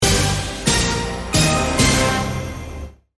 Play, download and share MUSICSTINGER original sound button!!!!
musicstinger_doqKqi3.mp3